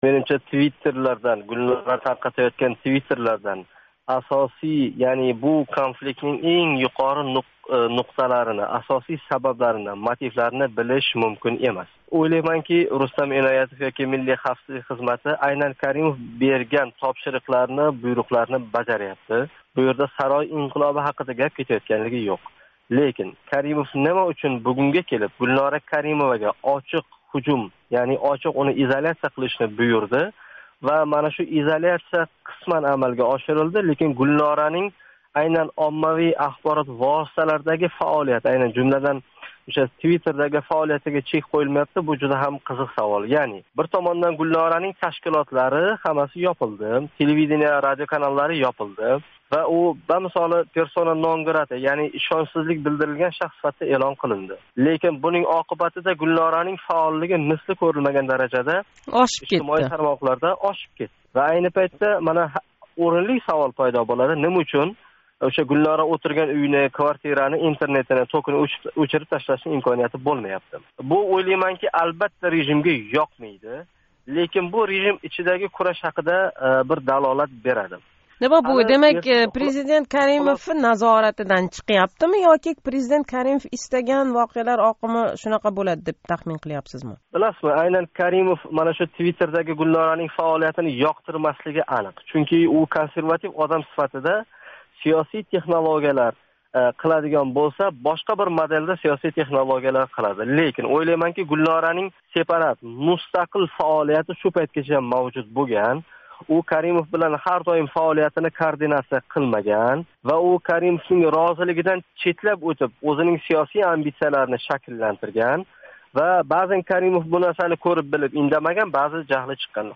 суҳбат.